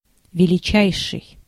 Ääntäminen
IPA : /ˈʌtməʊst/ IPA : /ˈʌtmoʊst/ IPA : [ˈʌʔmoʊst]